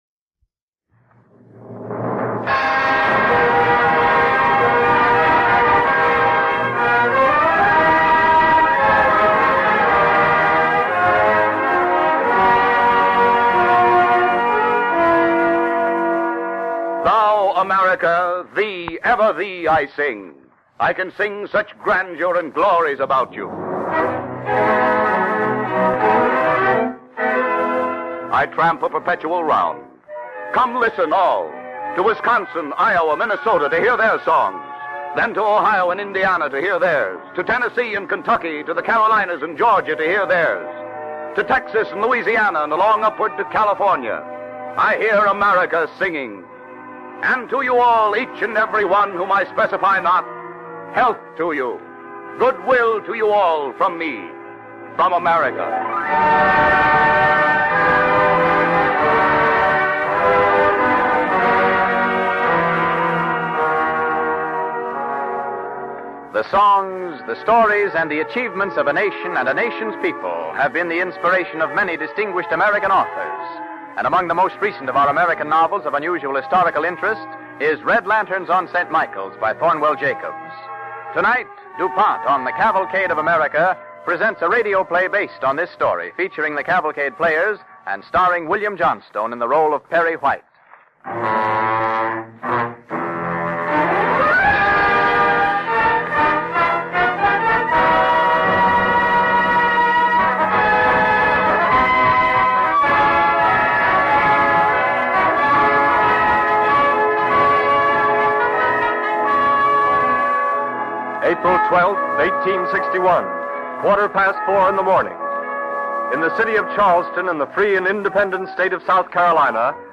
With announcer